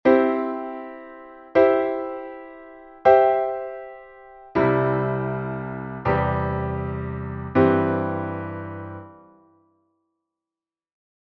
enge Lage versus weite Lage (Einstein 1929)